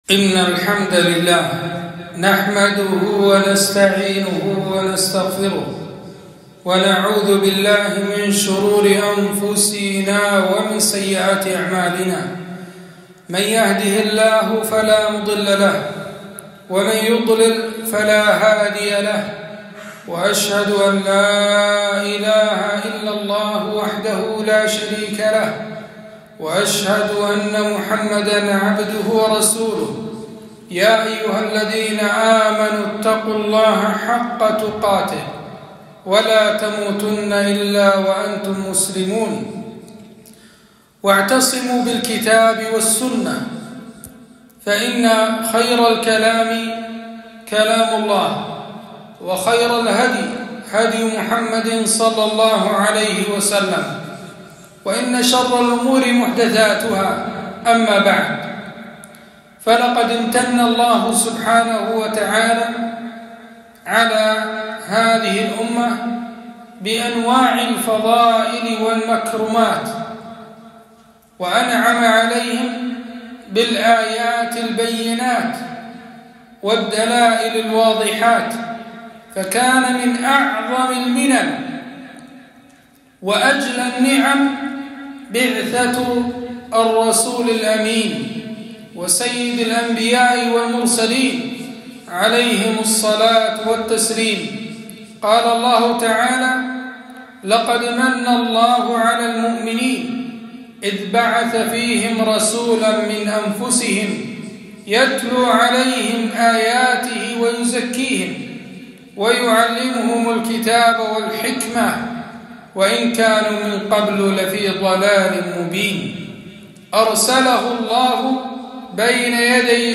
خطبة - اتبعوا ولا تبتدعوا